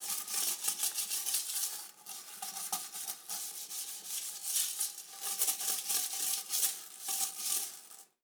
Toilet Brush Clean Sound
household